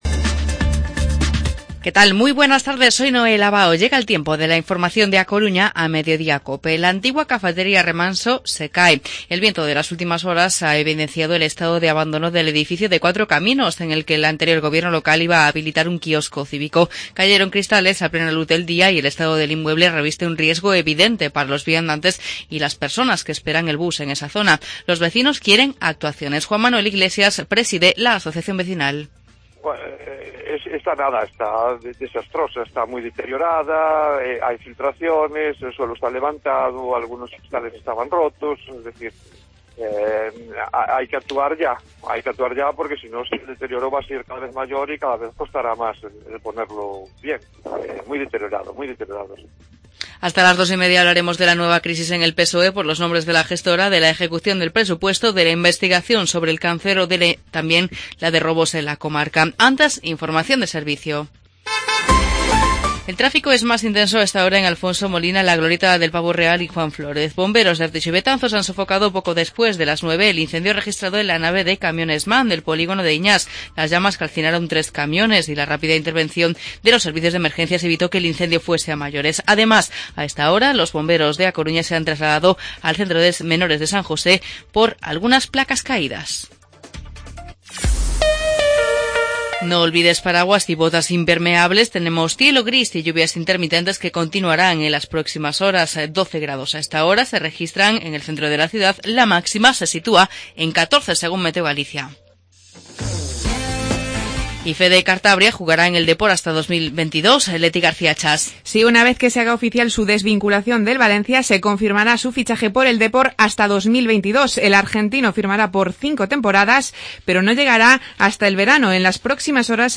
Informativo Mediodía COPE Coruña martes, 31 de enero de 2017